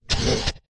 咆哮与怒吼
这听起来像一个愤怒的生物（有点像他正在发出警告）。
标签： 音频 警告 危险 怒吼 可怕的 声音的 恐怖的 伤害 效果 杀手 恐怖 生物 雪人 攻击 愤怒 咆哮 大脚 恶劣 暴力 雪人
声道立体声